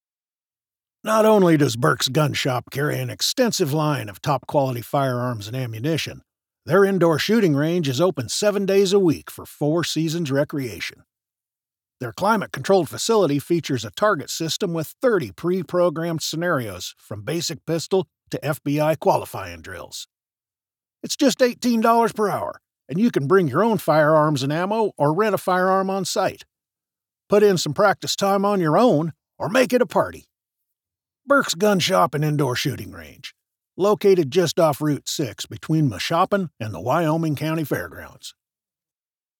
British Radio & TV Commercial Voice Overs Artists
Adult (30-50) | Older Sound (50+)